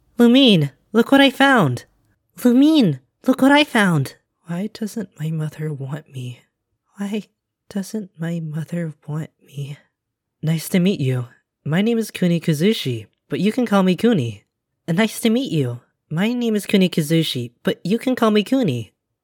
Voice: Up to interpretation, but must be youthful/younger sounding.